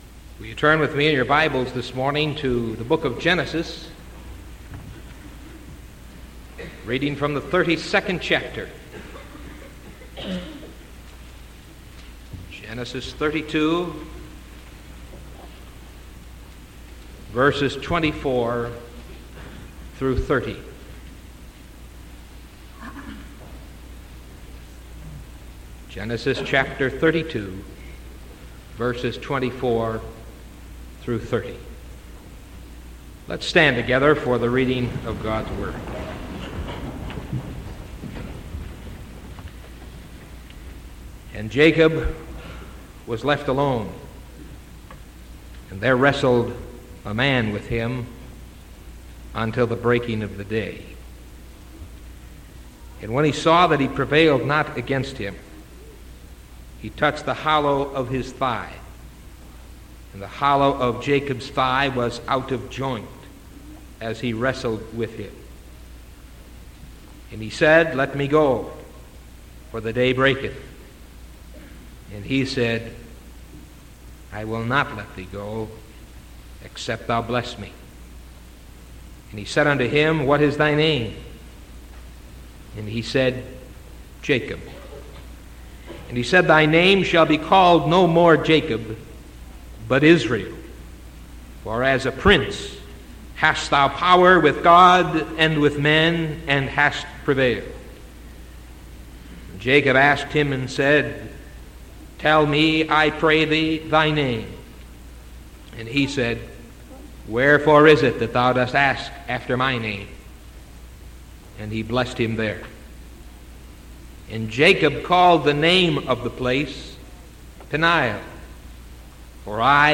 Sermon October 13th 1974 AM